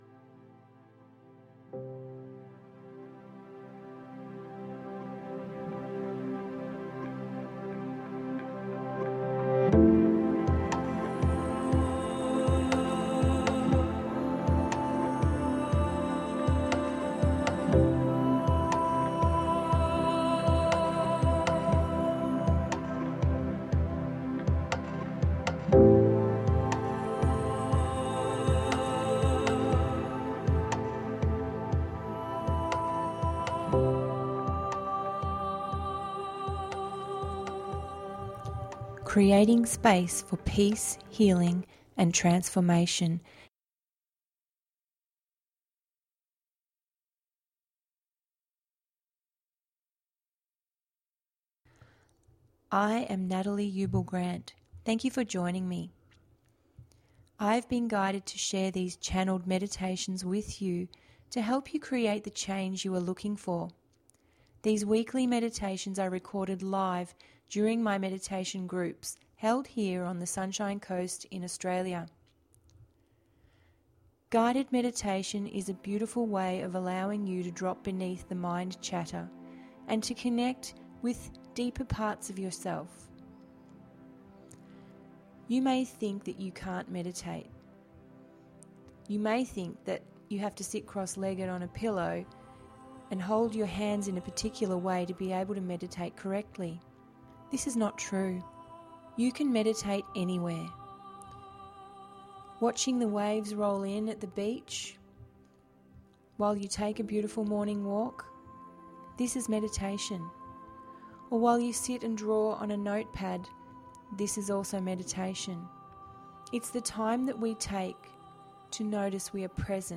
A deep meditation to connect you to the deeper parts of your soul.